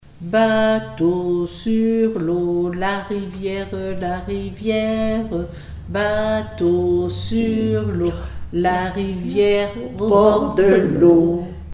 formulette enfantine : sauteuse
comptines et formulettes enfantines
Pièce musicale inédite